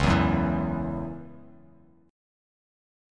Dbmbb5 chord